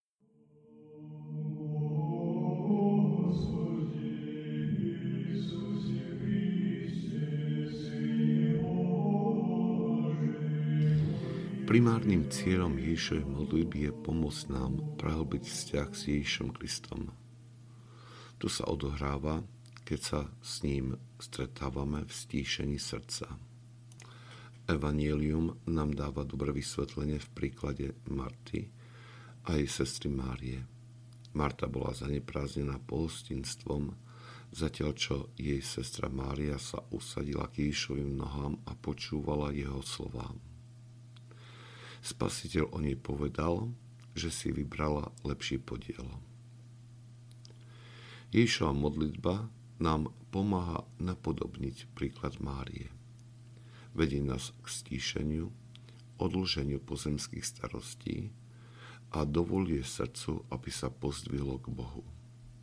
Ježišova modlitba audiokniha
Ukázka z knihy